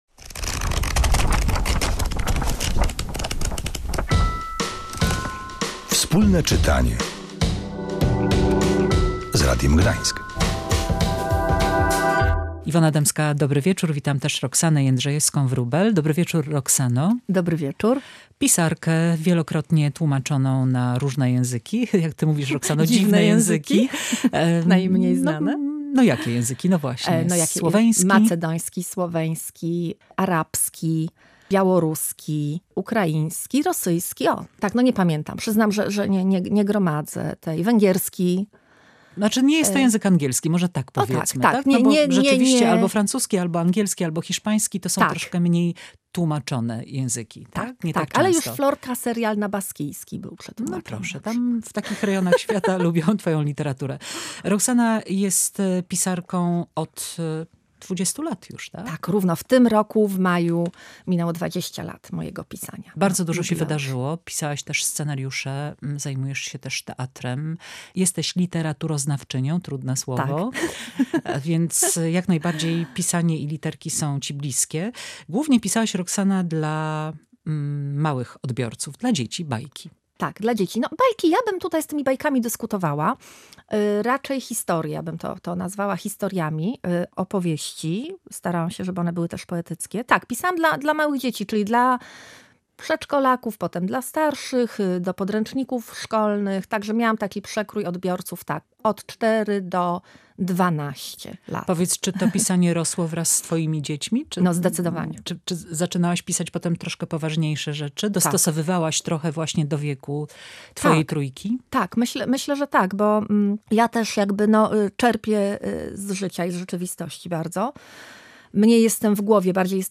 „W krainie snów”. Rozmowa